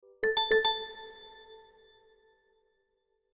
message-ringtone-21467.mp3